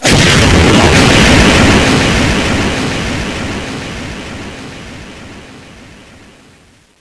flares.wav